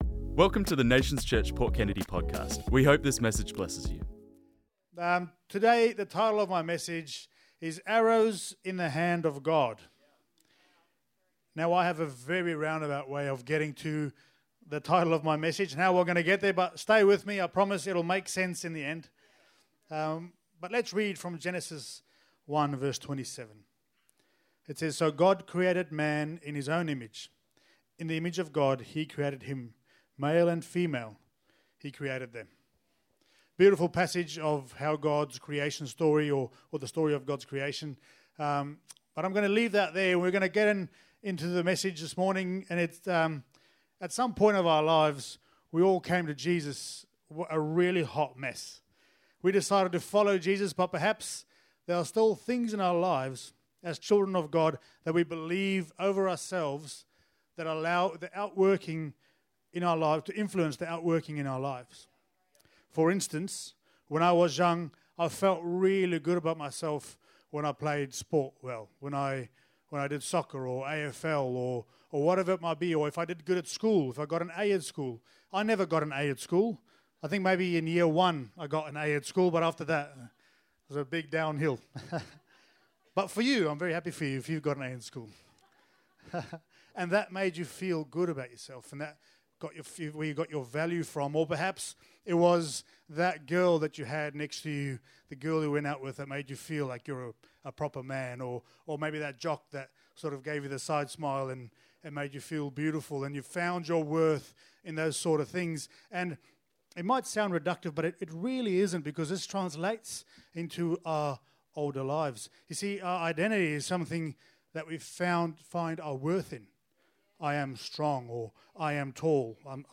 This message was preached on Sunday the 23rd November 2025